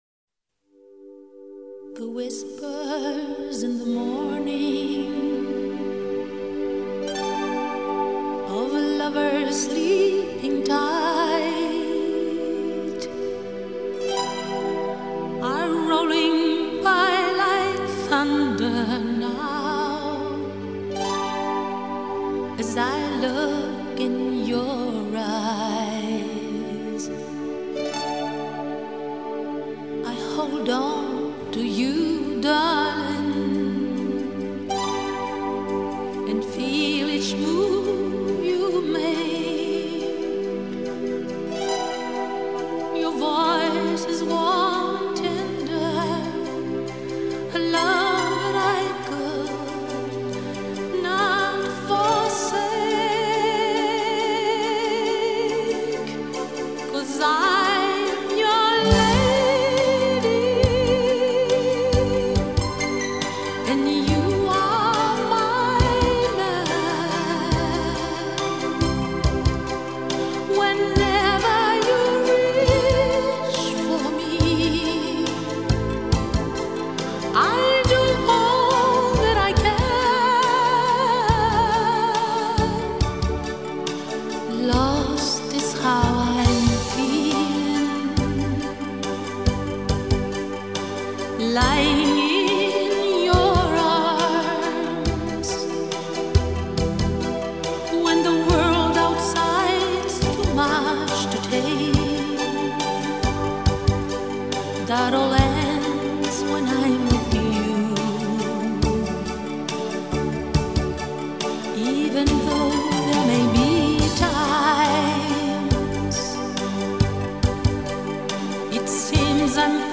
音乐类型：西洋音乐
她以古典声乐的品味升华了当代歌唱艺术的气质